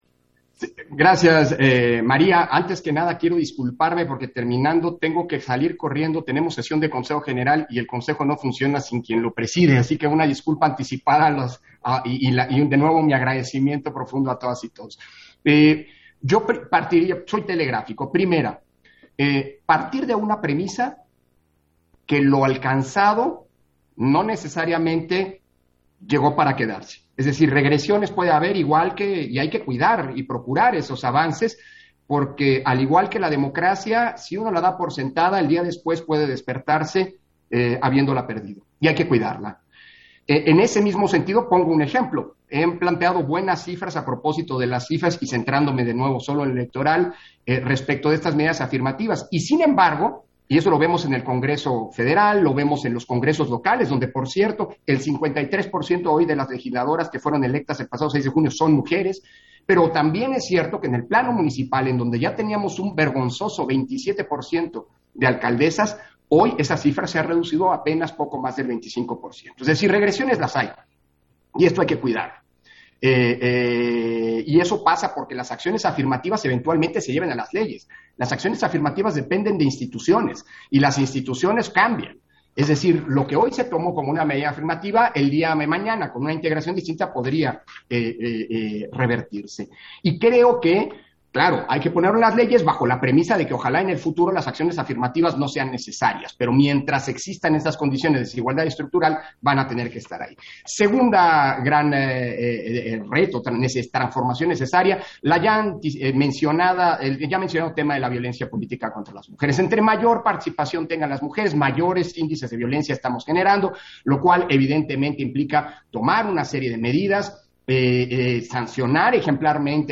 Intervenciones de Lorenzo Córdova, en el seminario regional, Democracias paritarias e Inclusivas: Desafíos en el contexto actual